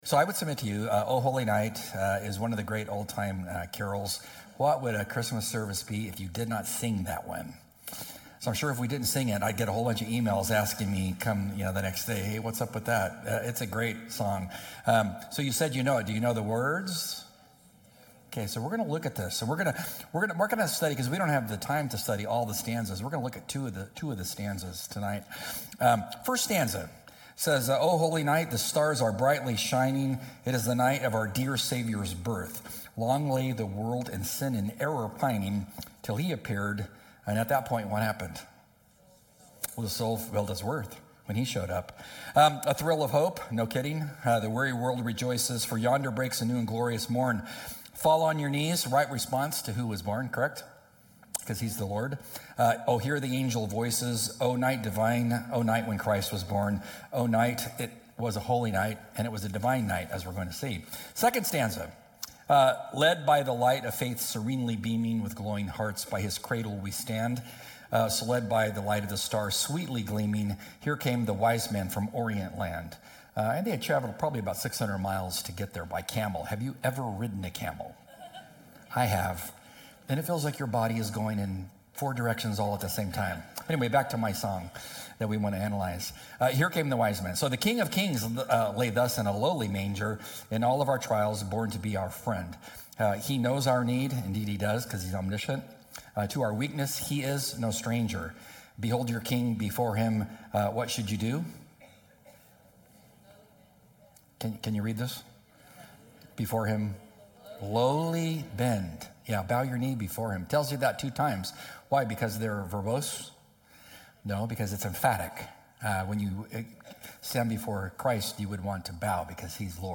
Burke Community Church